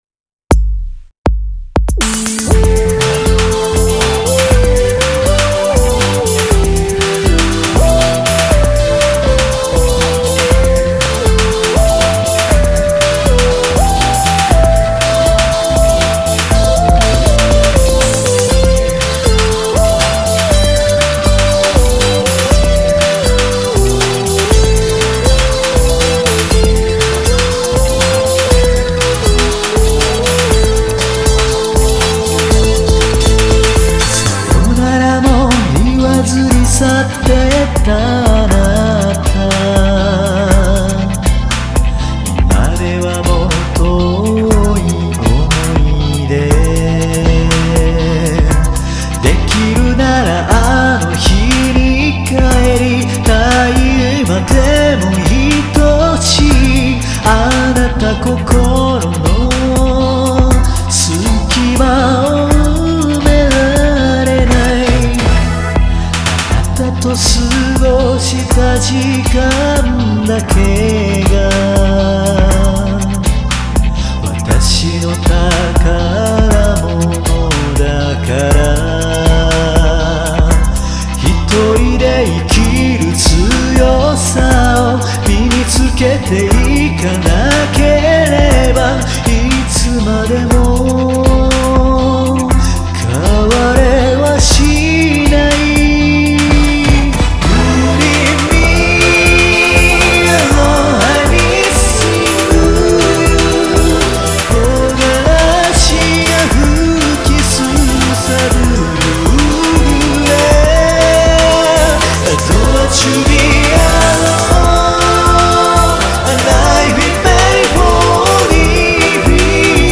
メロディラインがかなりＰＯＰな部分とＲ＆Ｂとを合体したような曲です。